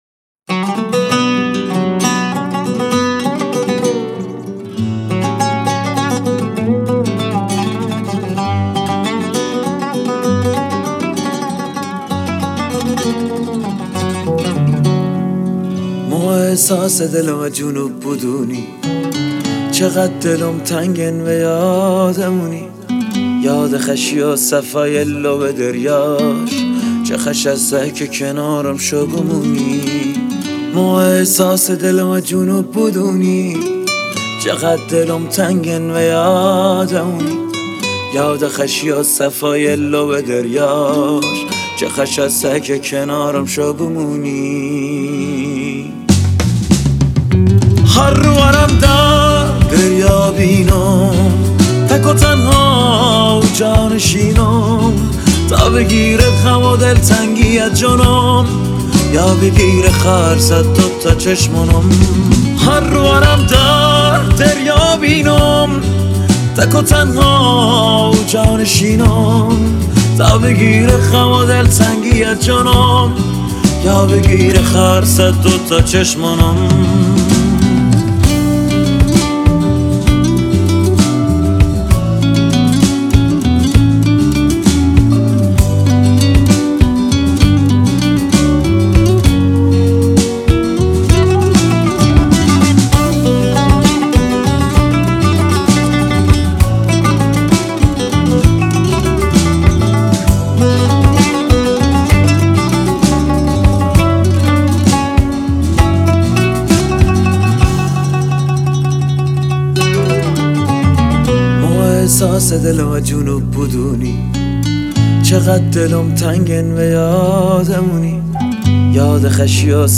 تنظیم و گیتار باس
عود
گیتار